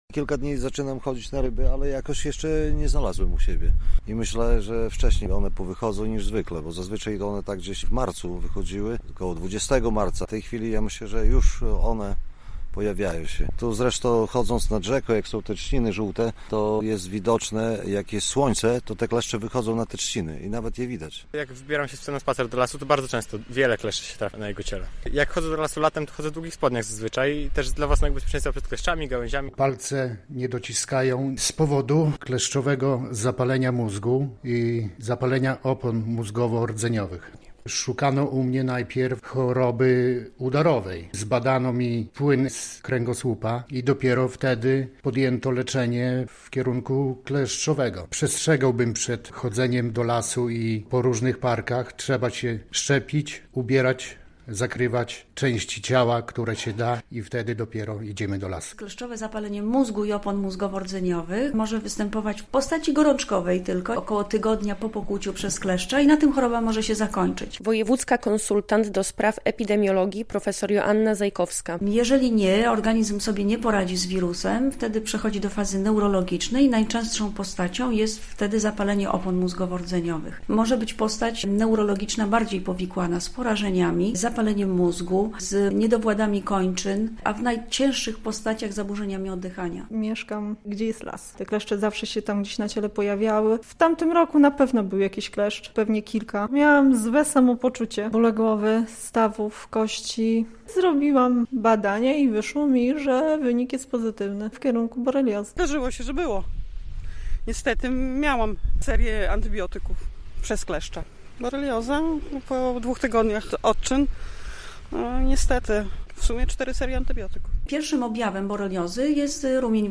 W woj. podlaskim wzrasta zachorowalność na kleszczowe zapalenie mózgu i boreliozę - relacja